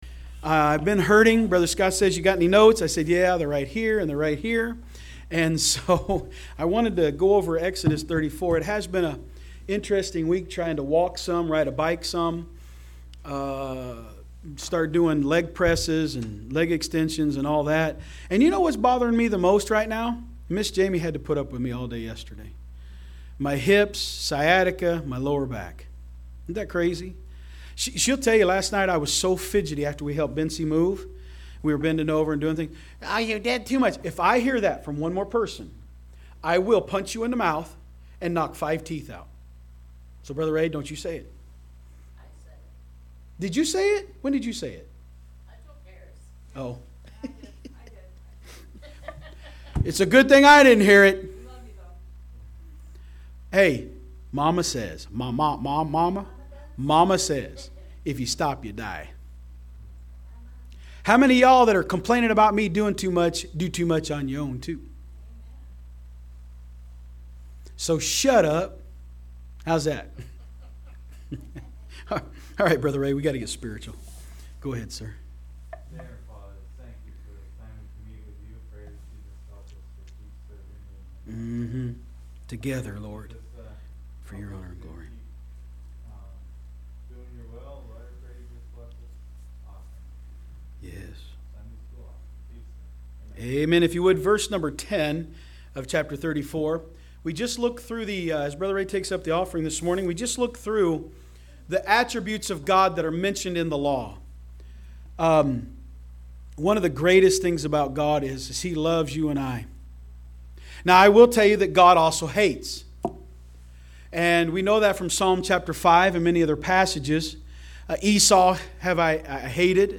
From Series: "Sunday School"